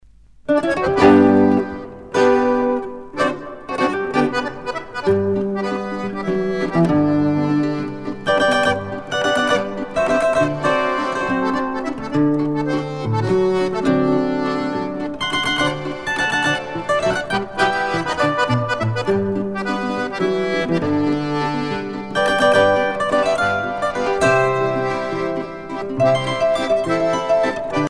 danse : valse
danse : ländler
Pièce musicale éditée